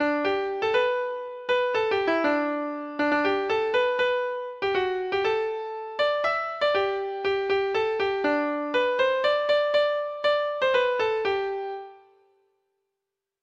Traditional Music of unknown author.